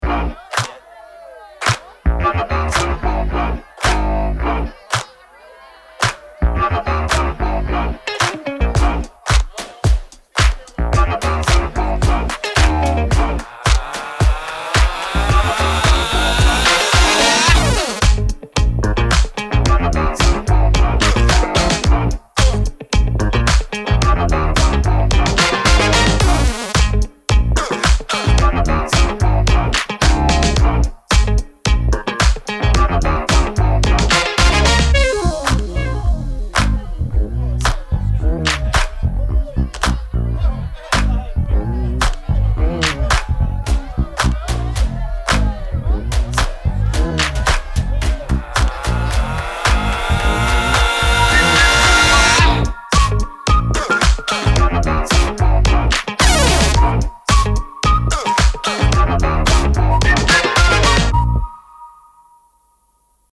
Saturation, warmth, and mechanical movement for your mixes.
to:Bias – Loudness Adapted
-3dB True Peak
These are real-session settings, not extreme demonstrations.